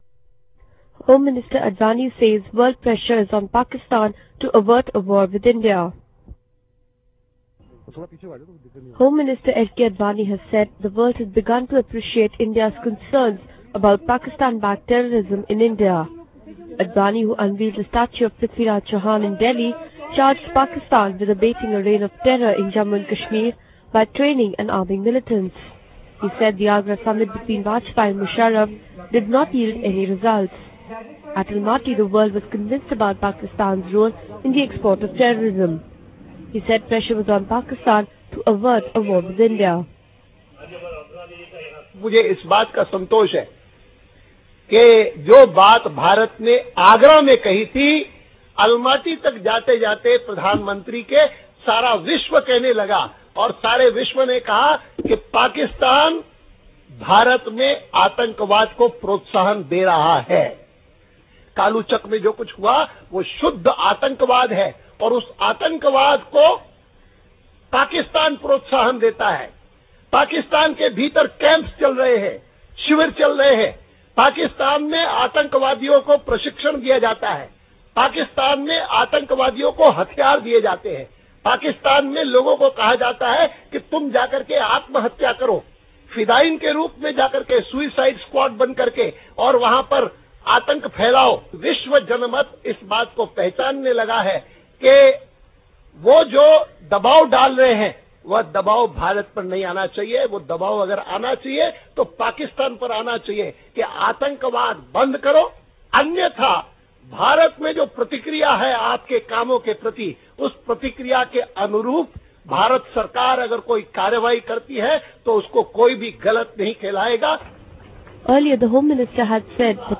Union Home Minister L. K. Advani addresses a gathering after inaugurating a conservation complex at Qila Rai Pithora in New Delhi on Friday.